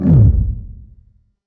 Transition Swoosh
A smooth, clean swoosh designed for scene transitions and segment changes
transition-swoosh.mp3